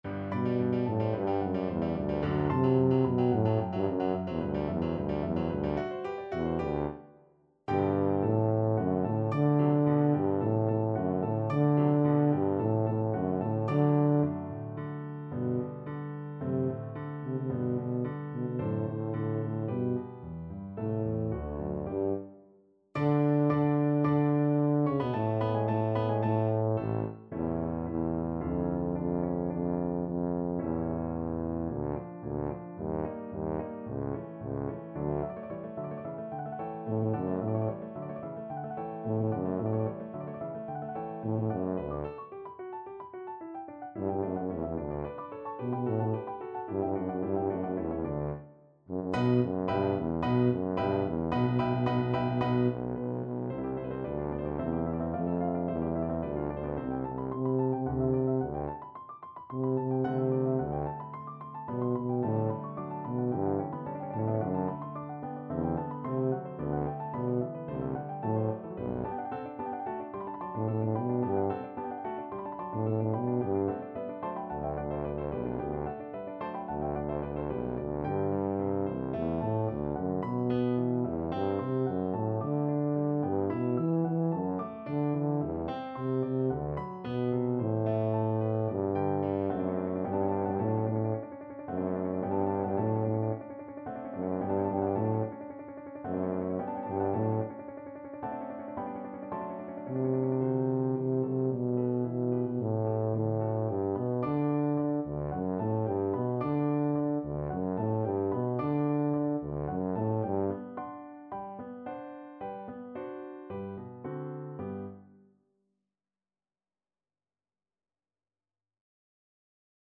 Classical Mozart, Wolfgang Amadeus Ah! pieta Signori miei from Don Giovanni Tuba version
Tuba
4/4 (View more 4/4 Music)
A2-E4
G major (Sounding Pitch) (View more G major Music for Tuba )
Allegro assai =220 (View more music marked Allegro)
Classical (View more Classical Tuba Music)